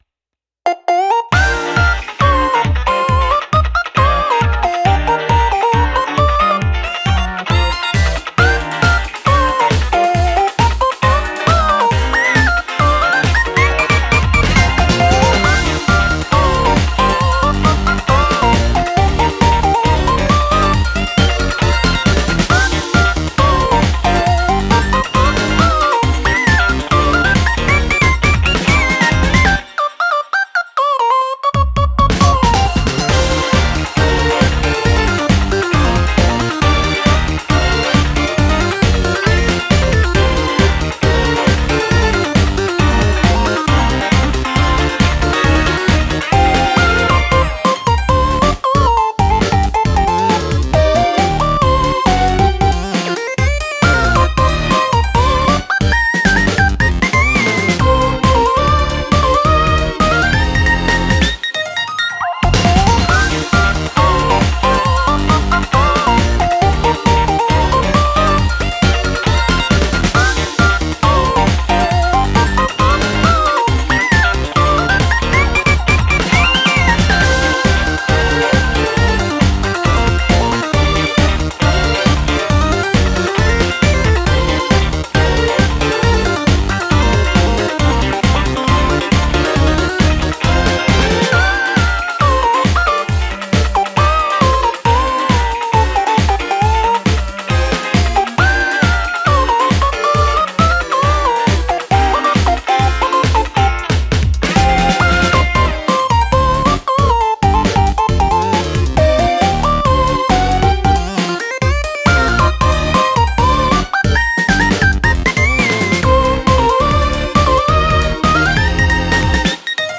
/ インスト